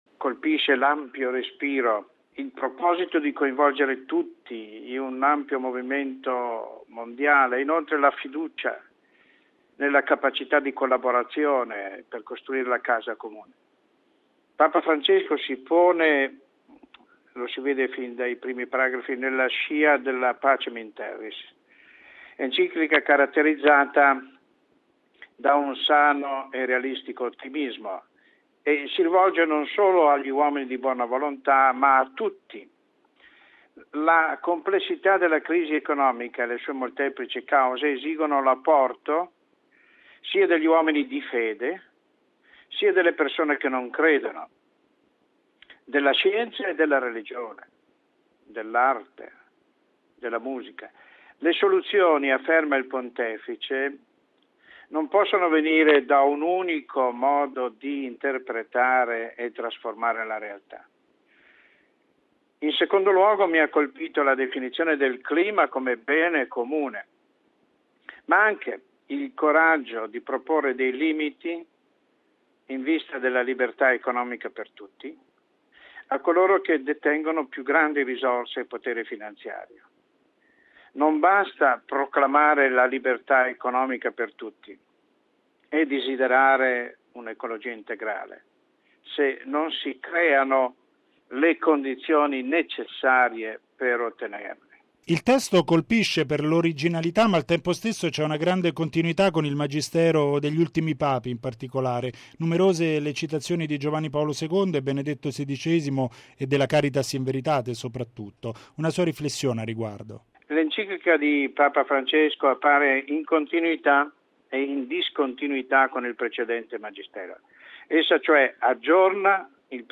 ha intervistato mons. Mario Toso, vescovo di Faenza